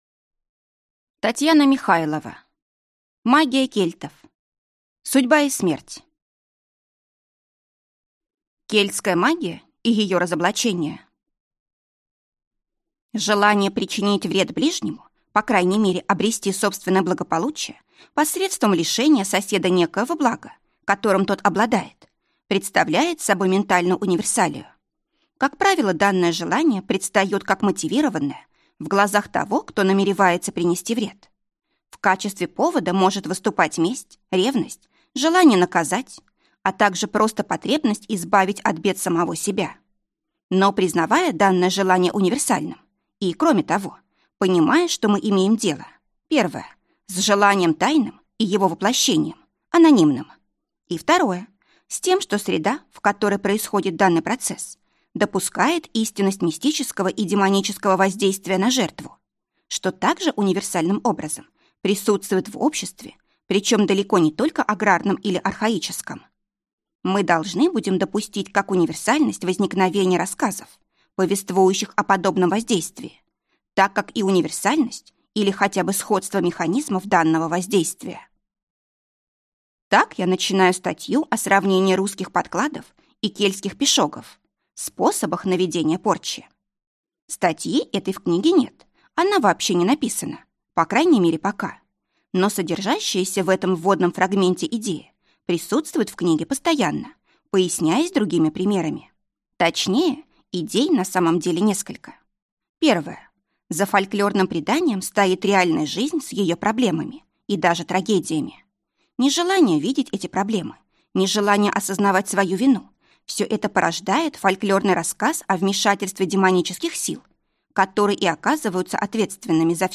Аудиокнига Магия кельтов: судьба и смерть | Библиотека аудиокниг